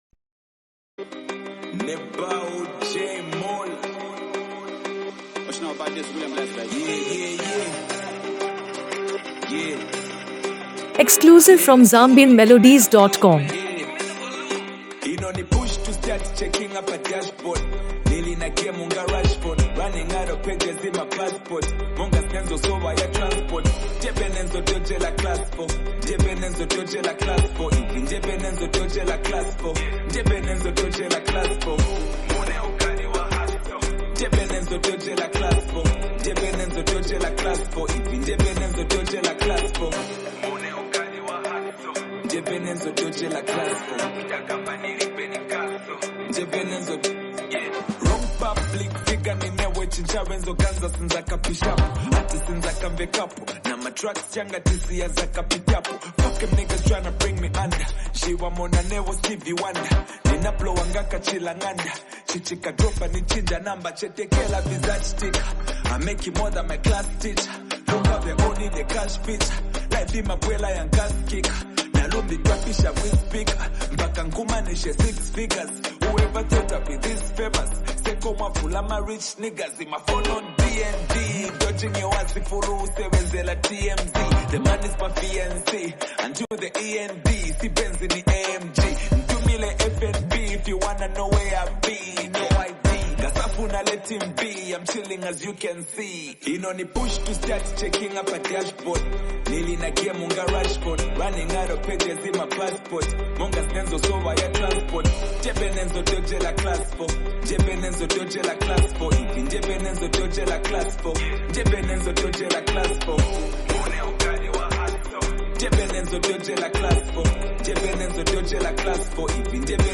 energetic and inspiring track
motivational hip-hop anthem with Afro-fusion elements
infectious beat